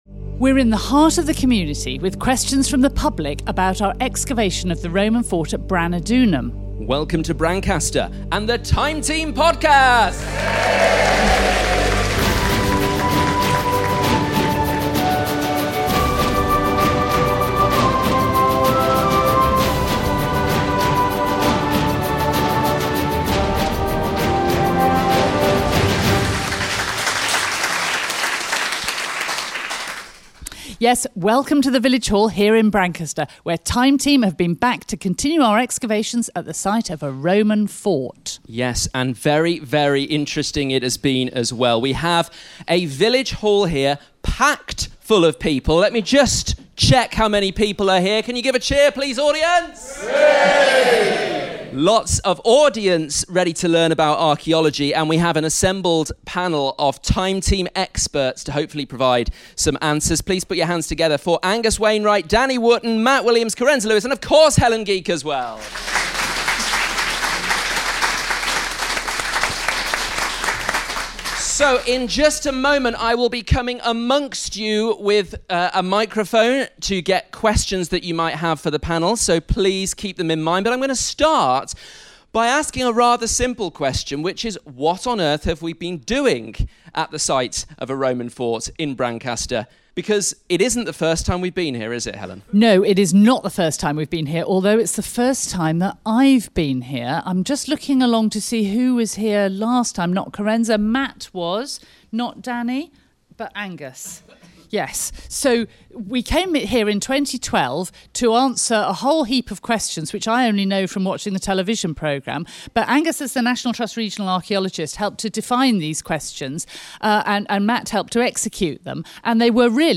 Time Team have just finished a three day excavation of the Roman fort at Brancaster in Norfolk. On the evening of our second day on site the team headed to the local village hall where people in the community gathered to hear about some of the discoveries archaeologists had made.